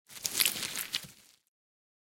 Звуки осьминога
Шум передвижения осьминога